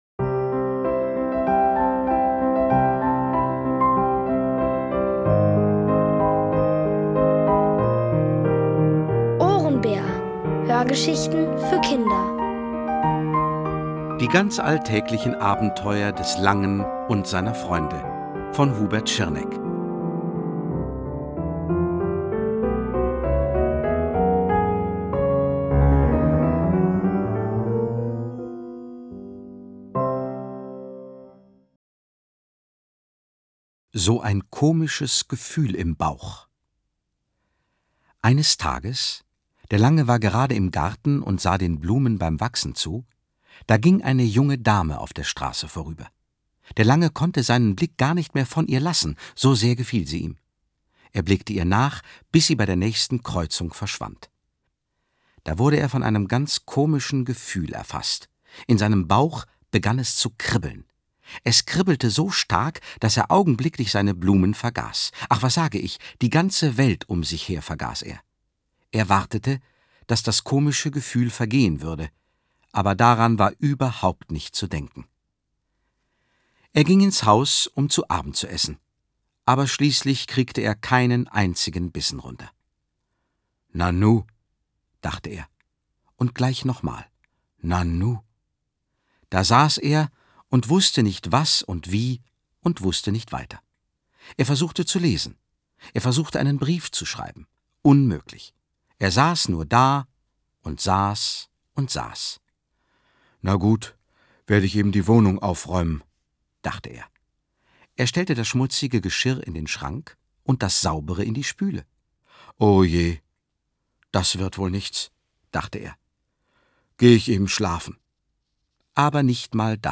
Von Autoren extra für die Reihe geschrieben und von bekannten Schauspielern gelesen.
liest: Gerd Wameling.